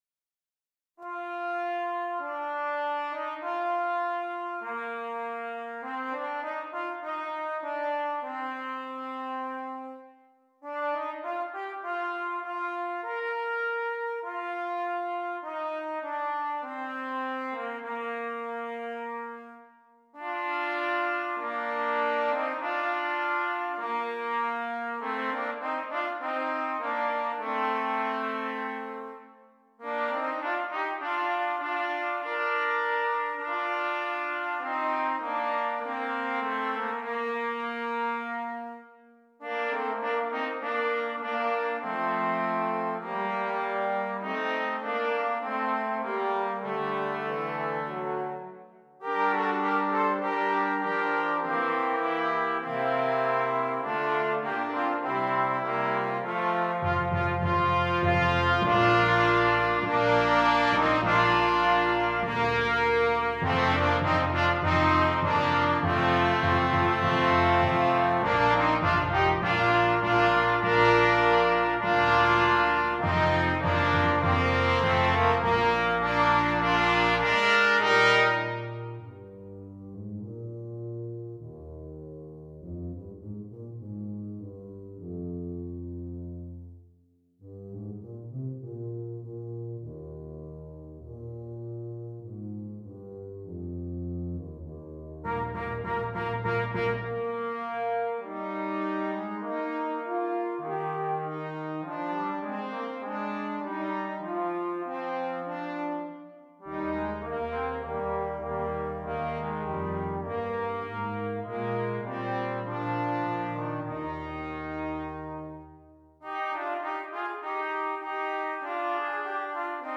Brass Quintet
Each instrument has a section of melody-even the tuba!